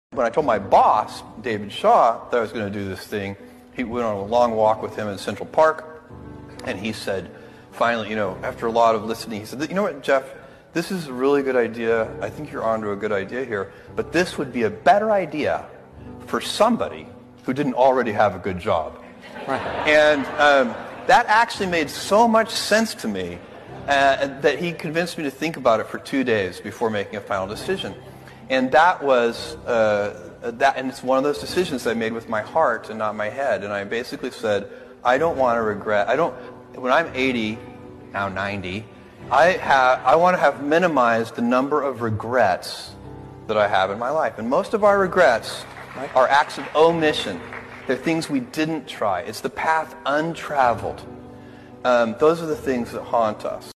Jeff Bezos speaking about how he made the decision to quit his lucrative job and start Amazon A great framework to help you to live a life without regrets.